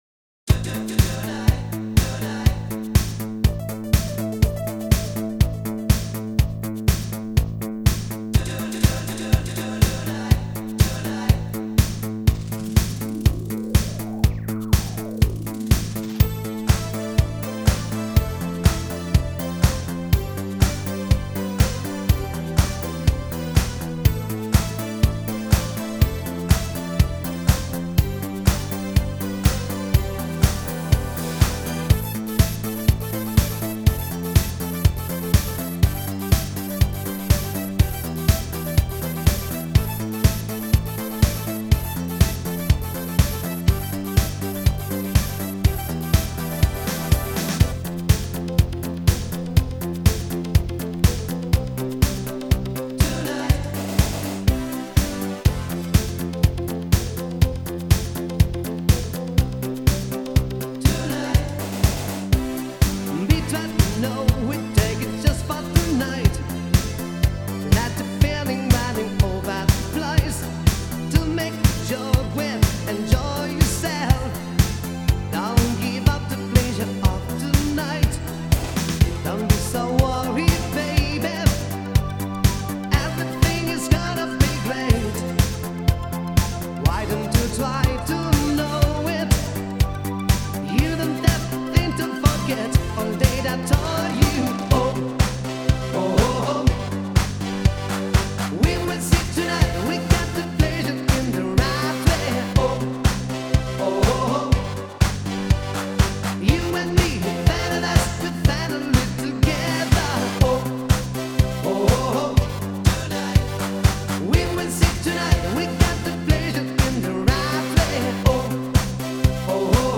- это уже классический евробит